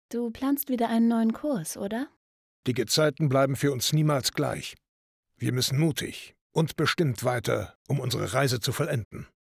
Voice-Over Recordings